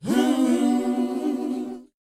WHOA A DD.wav